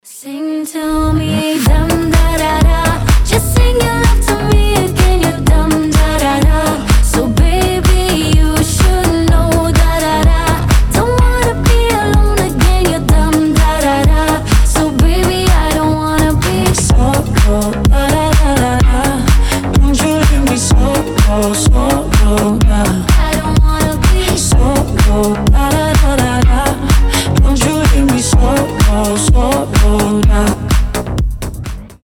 • Качество: 320, Stereo
заводные
женский голос
Dance Pop